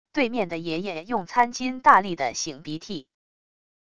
对面的爷爷用餐巾大力地擤鼻涕wav音频